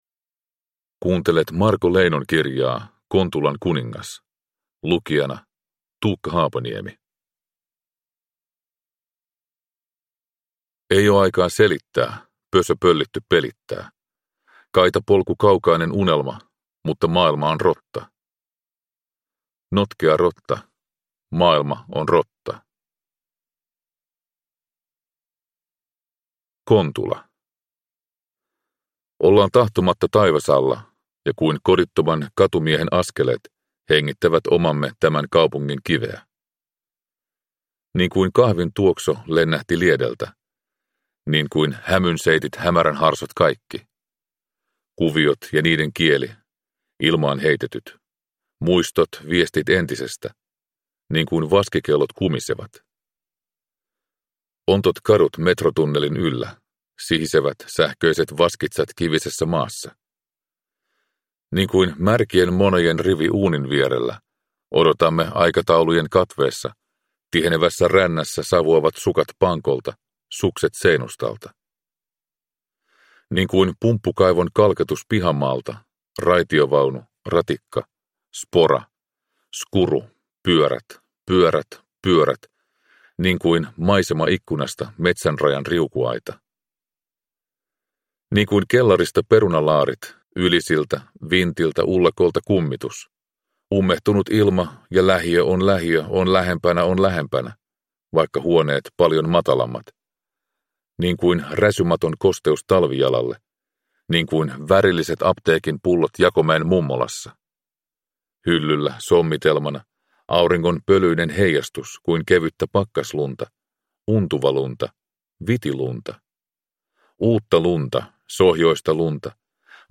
Kontulan kuningas – Ljudbok
Kontulan kuningas -äänikirja on muokattu versio alkuperäisestä sähkökirjalaitoksesta.